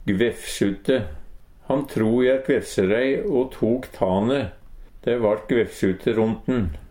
gvefsute - Numedalsmål (en-US)
Høyr på uttala Ordklasse: Adjektiv Attende til søk